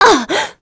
Scarlet-Vox_Damage_01.wav